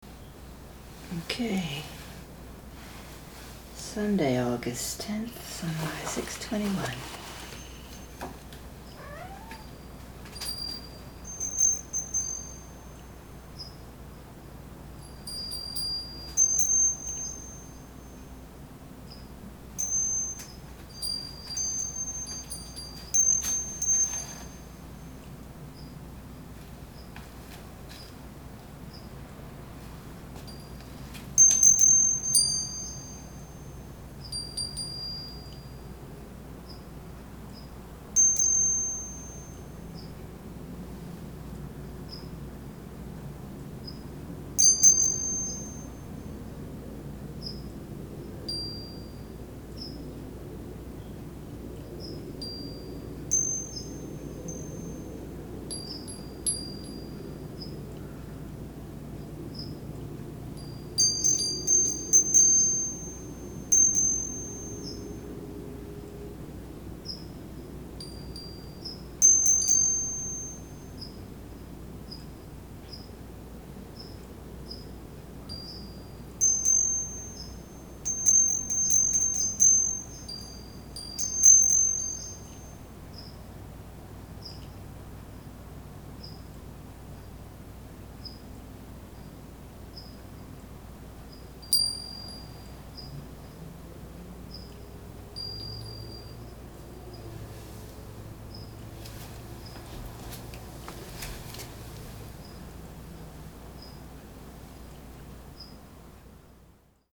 At least I thought I would avoid the wind and make a quiet recording.
there’s no wind noise for a change (audio).